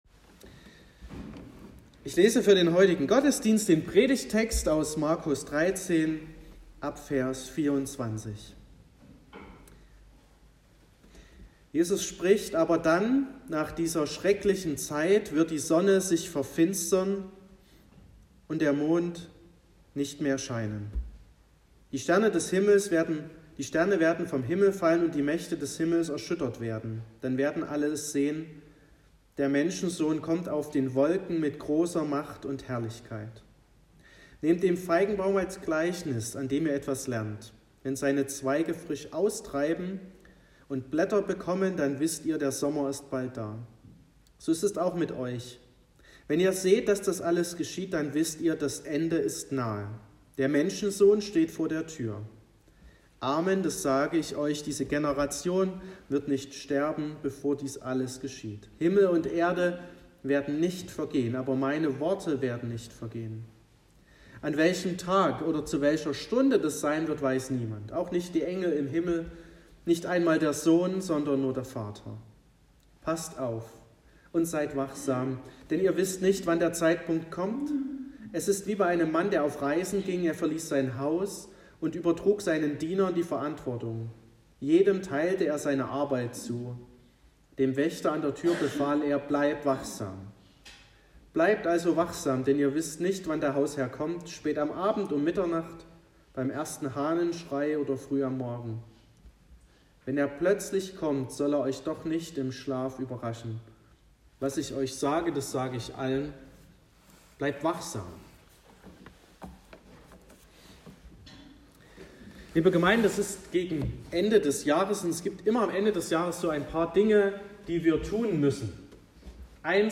Predigt und Aufzeichnungen
Predigt (Audio): 2022-11-20_Wachet_-_das_Ende__Jesus___der_Himmel_kommt.m4a (7,8 MB)